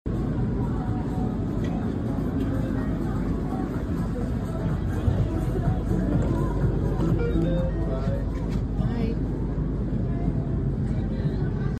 That paddock beeping sound 🤌🏻 sound effects free download
See you again next year Montreal!